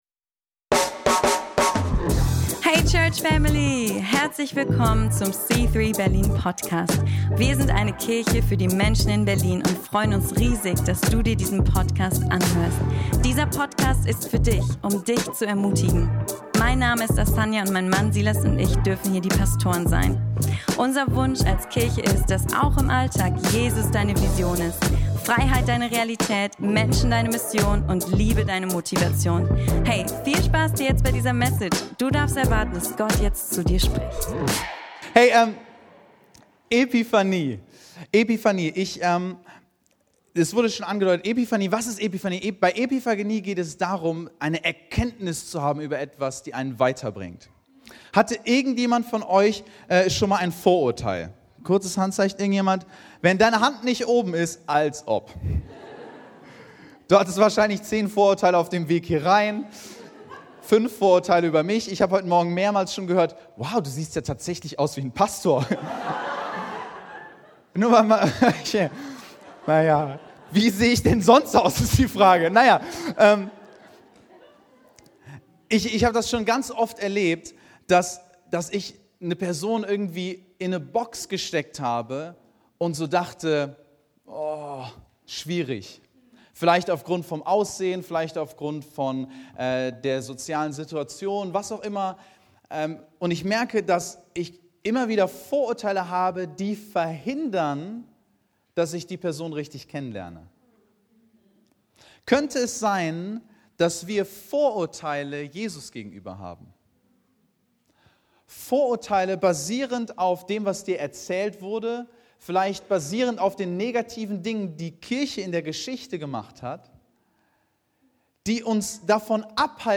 Dann hör in diese Predigt rein, in der es um den wahren Frieden geht, den nur Jesus dir geben kann.